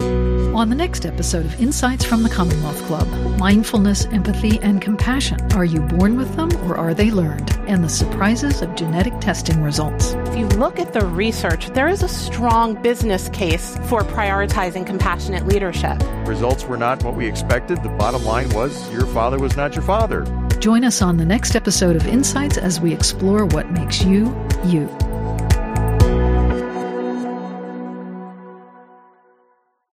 Ep001 Promo :30